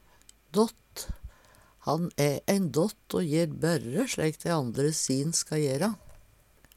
dått - Numedalsmål (en-US)